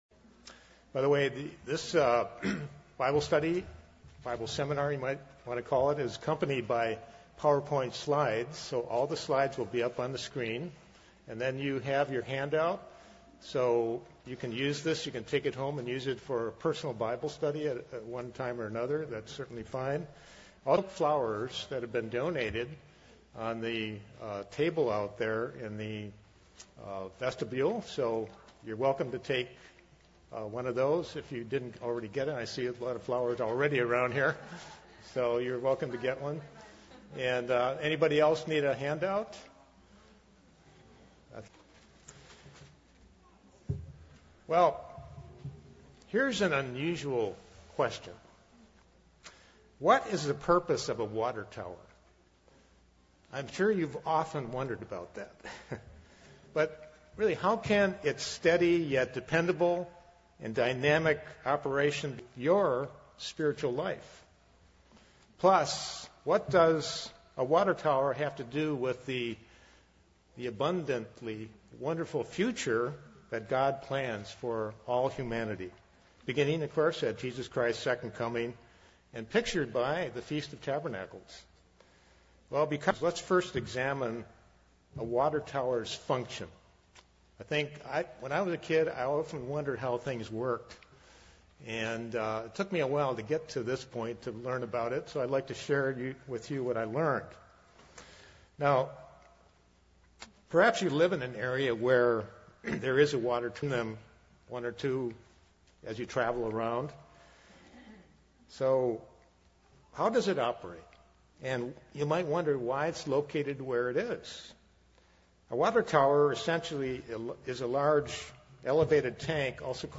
This sermon was given at the Pewaukee, Wisconsin 2022 Feast site.